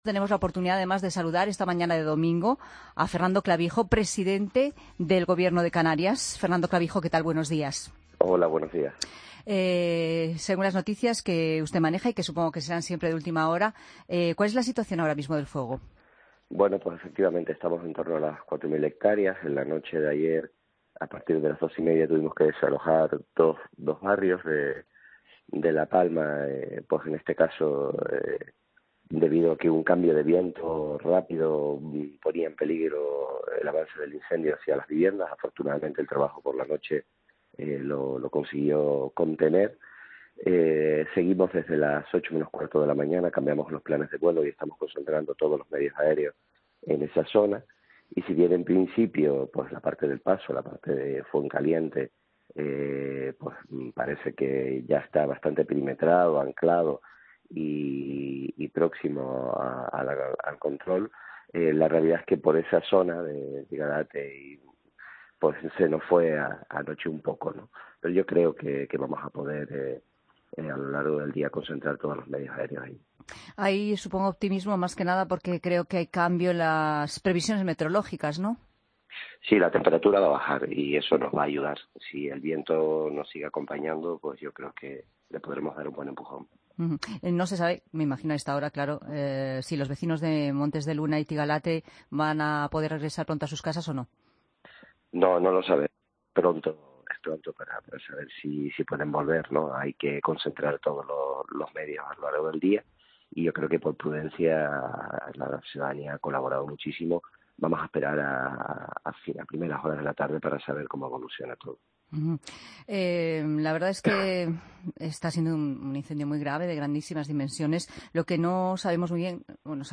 AUDIO: Entrevista a Fernando Clavijo, presidente del Gobierno de Canarias, en 'Fin de Semana' sobre el incendio de La Palma